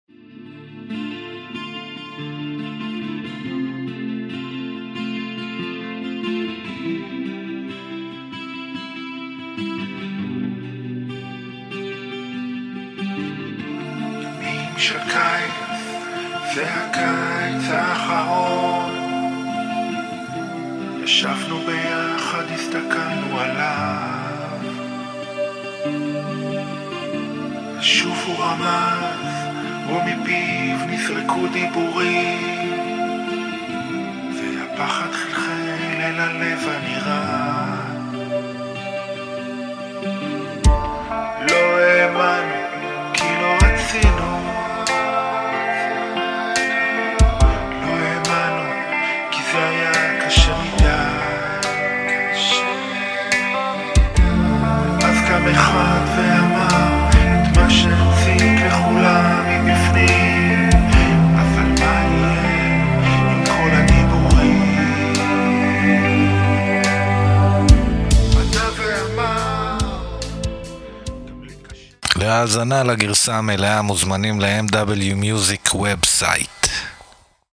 הרכב רוק־אינדי עברי חד, חצוף ועמוק
געגוע בקצב של ברזל לוהט.